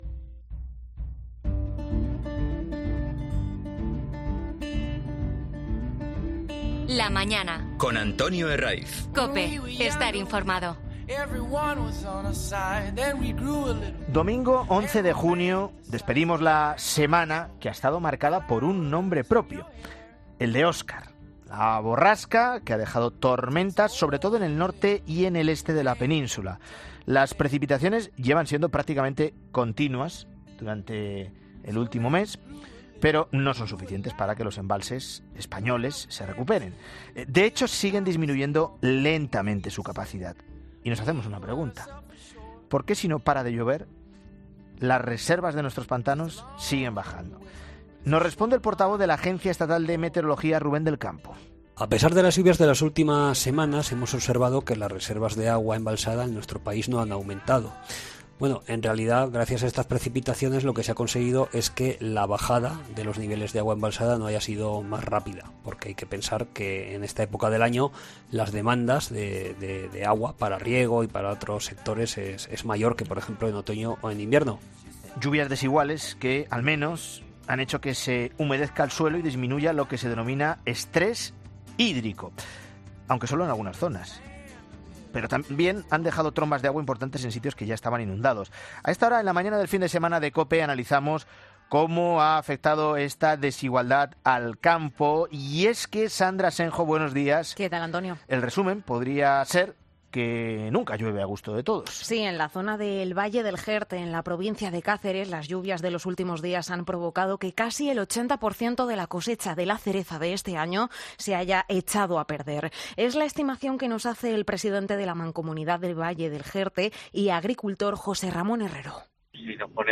En el Valle del Jerte se ha perdido el 80% de la cosecha de cerezas. Agricultores de Cáceres, Almería y Zaragoza cuentan en La Mañana del Fin de Semana las trágicas consecuencias